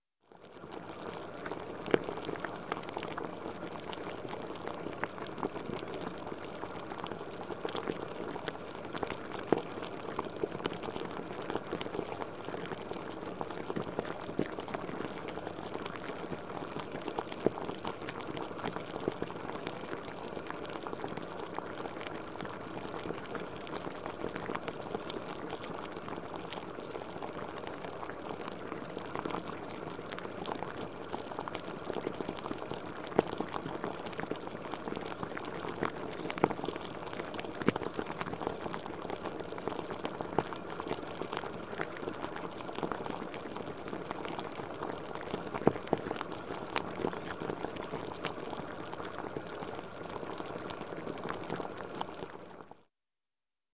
Звуки увлажнителя воздуха
увлажняет воздух в офисе есть такой вариант